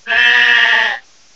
cries
dubwool.aif